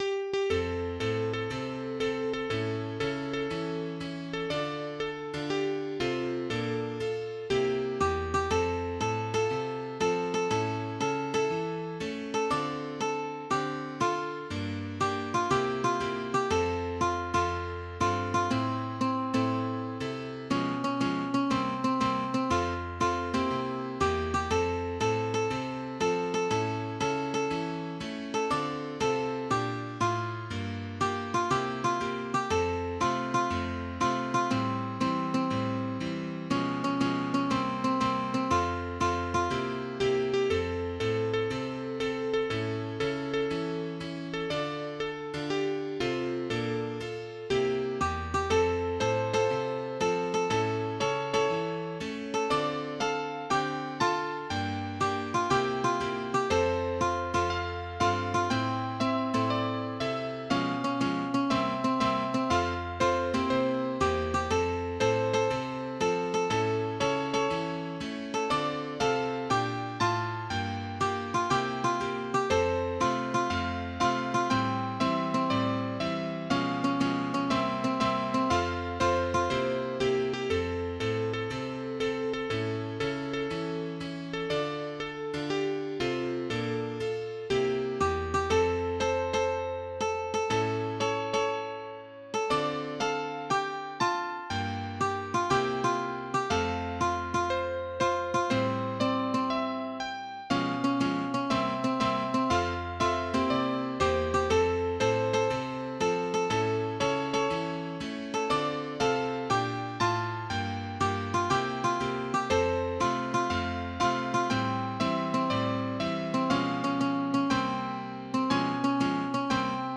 Een ritmisch liedje over het